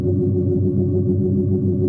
hum.wav